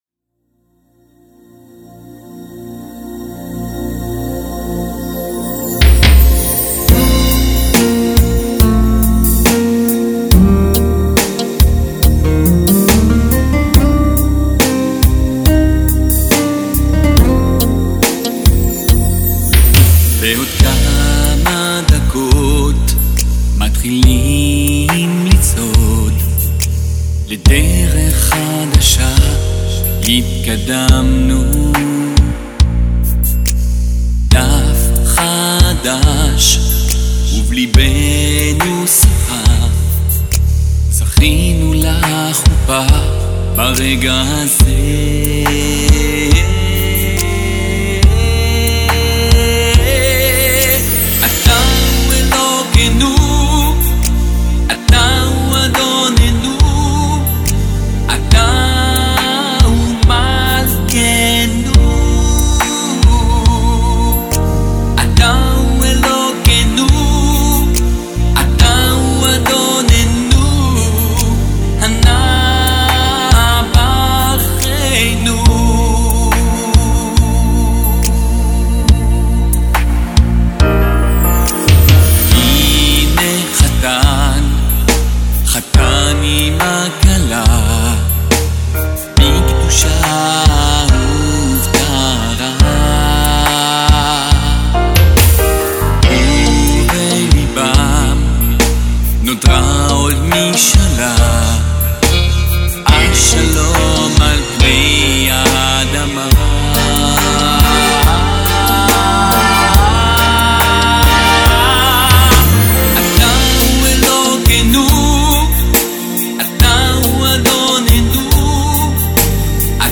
מתאים במיוחד כשיר חופה.